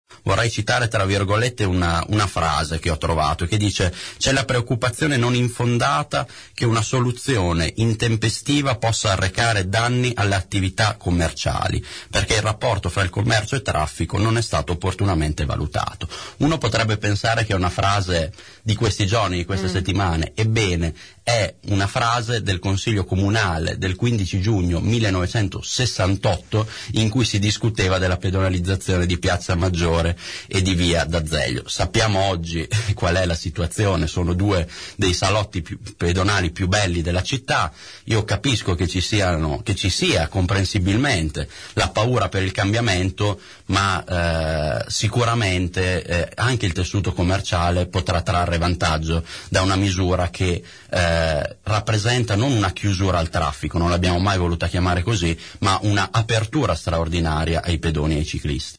Quanto ai commercianti, Colombo ha voluto citare in radio alcuni discorsi che venivano fatti in consiglio comunale nel 1968, quando ci si interrogava sulle conseguenze della pedonalizzazione di piazza Maggiore e via d’Azeglio.